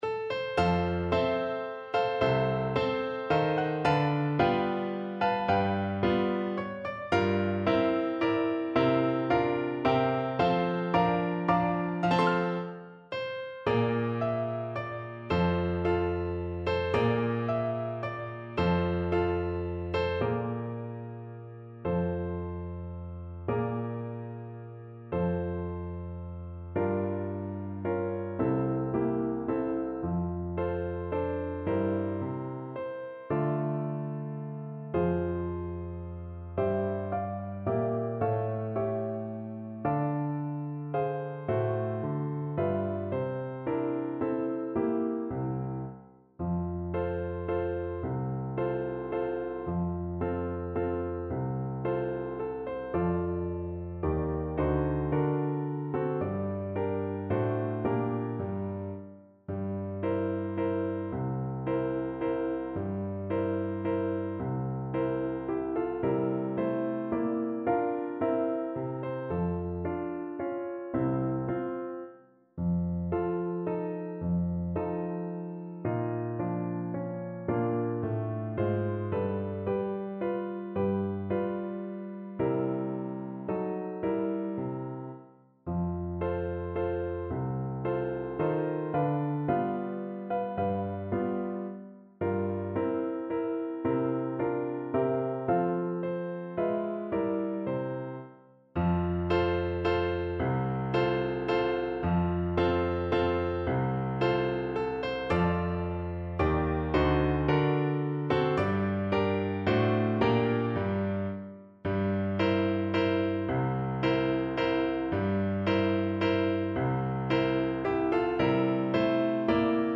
3/4 (View more 3/4 Music)
~ = 110 Valse moderato
Classical (View more Classical Clarinet Music)